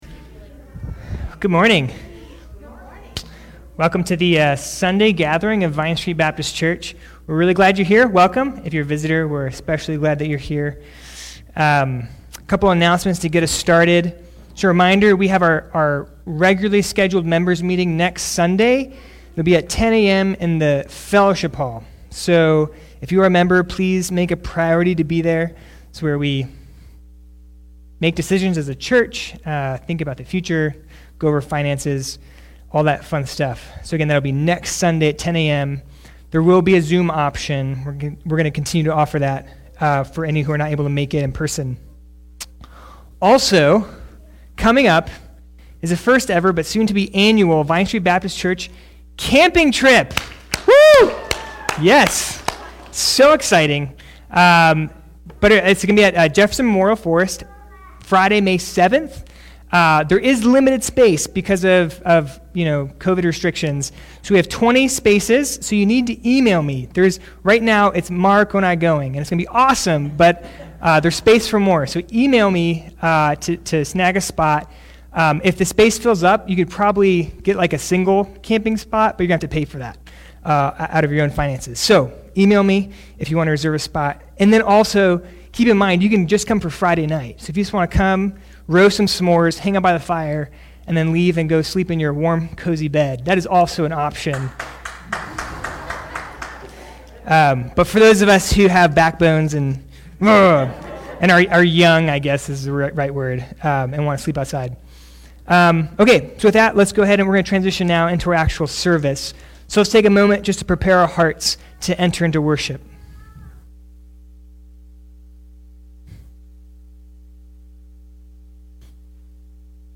April 11 Worship Audio – Full Service